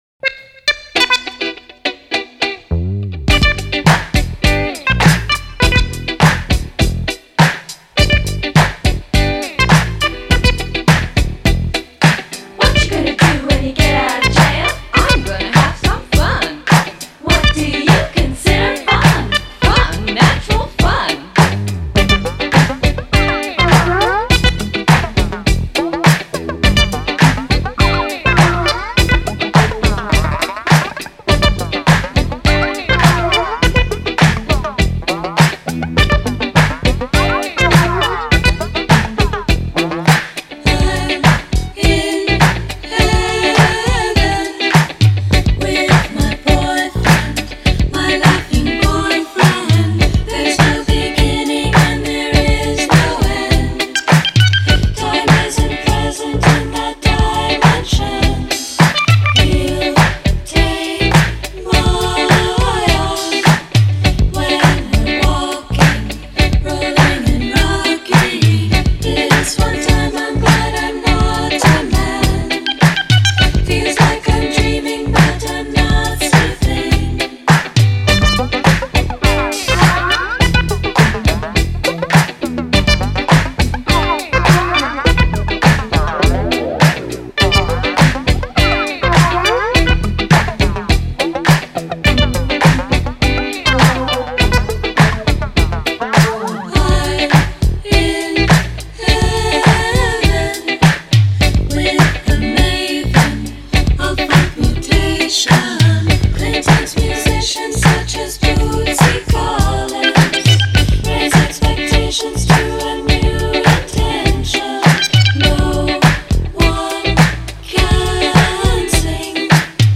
Obsédant ce génie de l'amour...